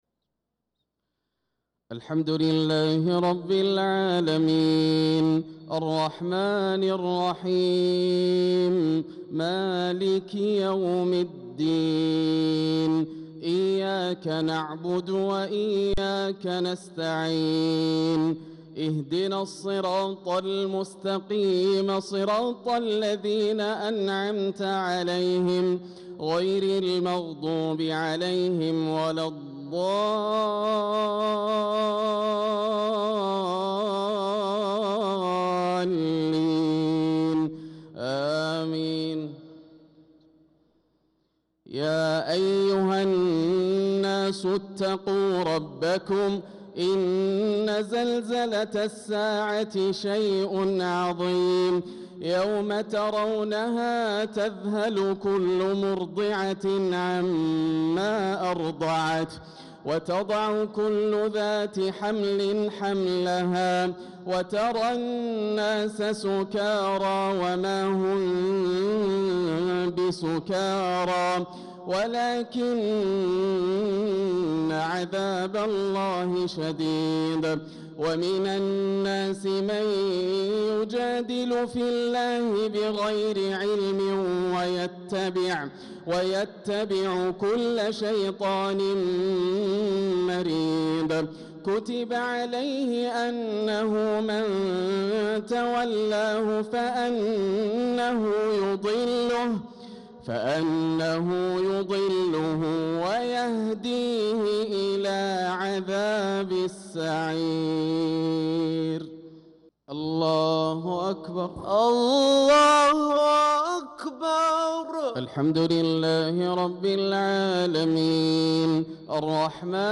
صلاة المغرب للقارئ ياسر الدوسري 14 ذو الحجة 1445 هـ
تِلَاوَات الْحَرَمَيْن .